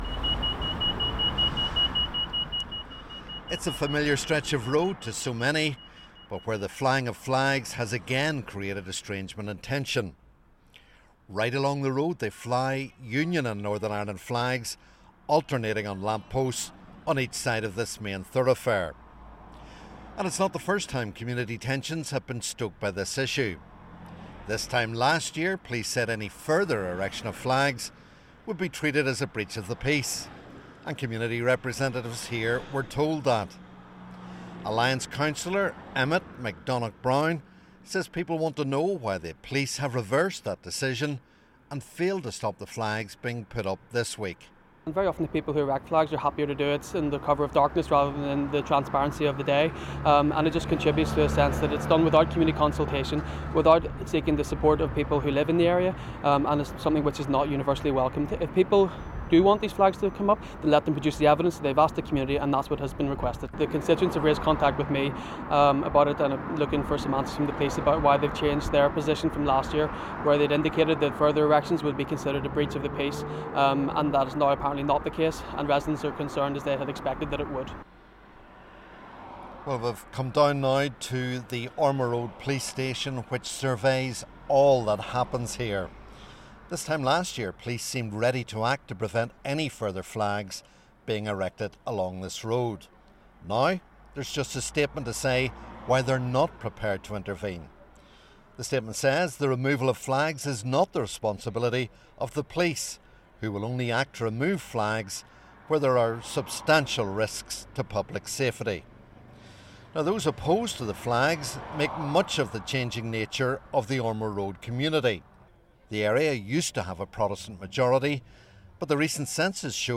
hears what residents on the Ormeau Road think about Unionist flags in their area.